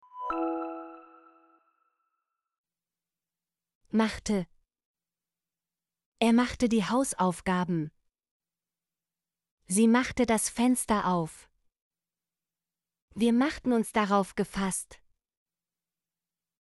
machte - Example Sentences & Pronunciation, German Frequency List